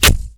rogue_melee.ogg